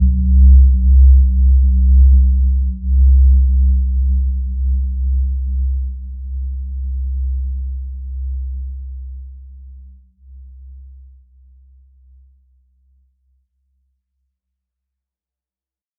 Gentle-Metallic-3-C2-mf.wav